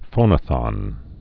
(fōnə-thŏn)